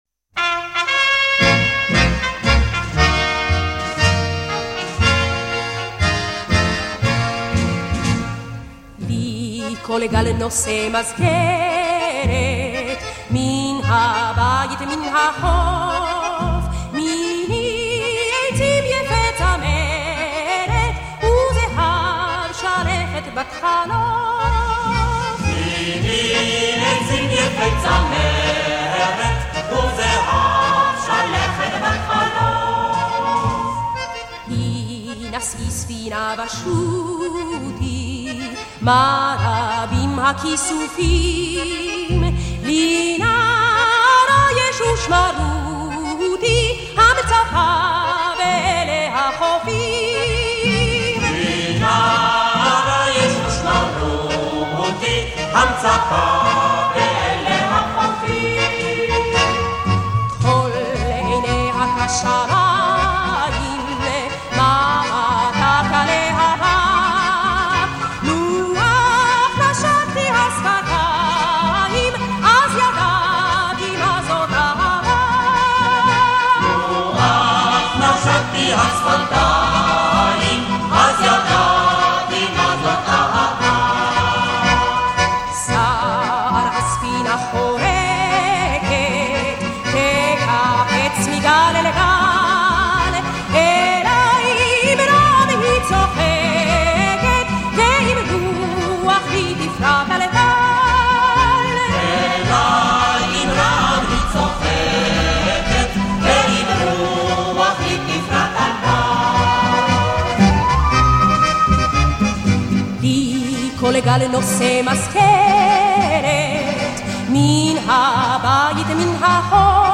Очень колоритная интерпретация мелодии